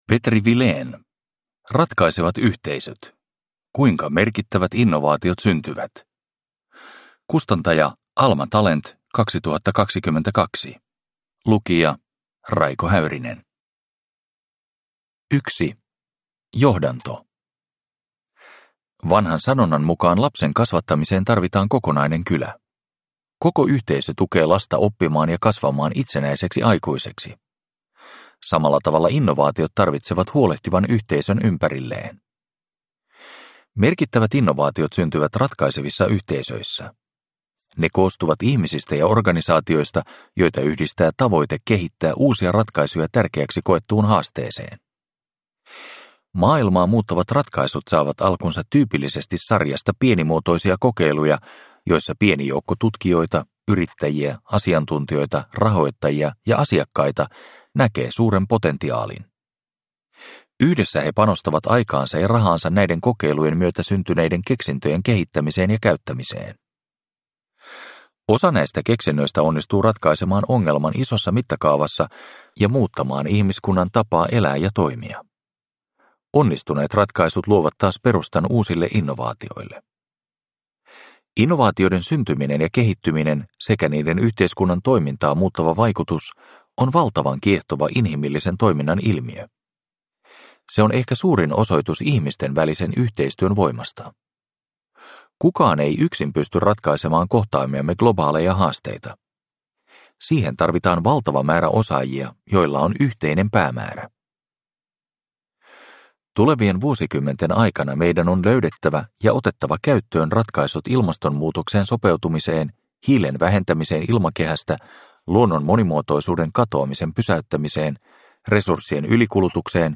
Ratkaisevat yhteisöt – Ljudbok – Laddas ner